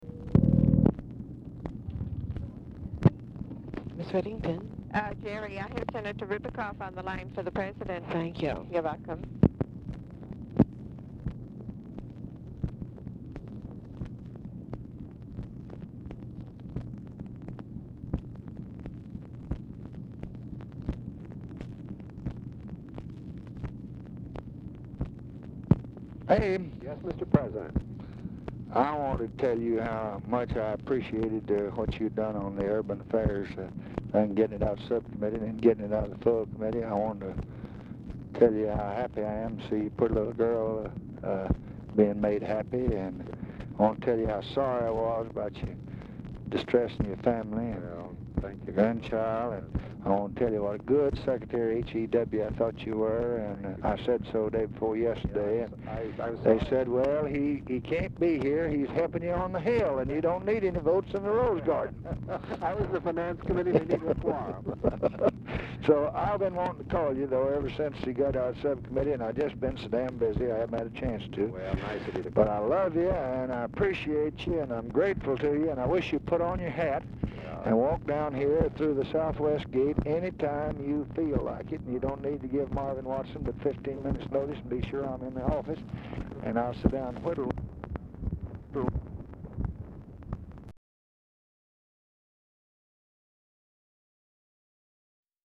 Telephone conversation # 8573, sound recording, LBJ and ABRAHAM RIBICOFF, 8/19/1965, 5:33PM | Discover LBJ
Format Dictation belt
Location Of Speaker 1 Oval Office or unknown location